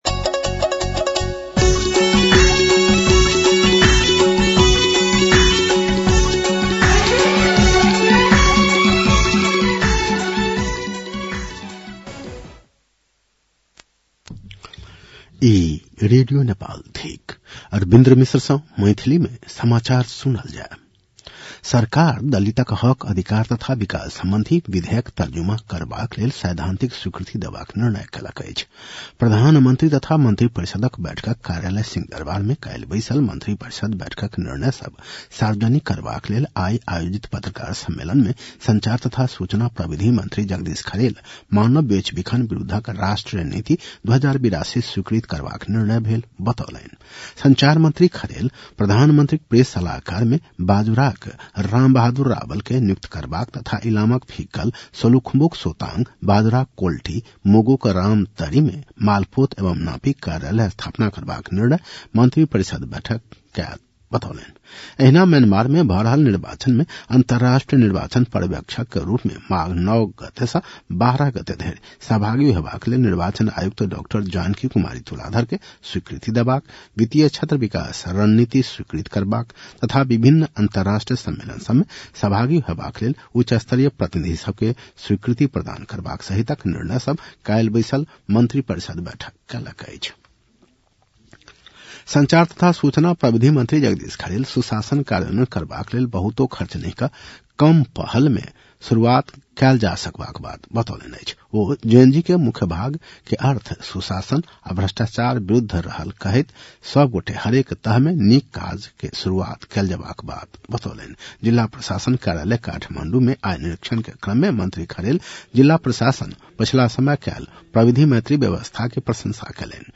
मैथिली भाषामा समाचार : २९ पुष , २०८२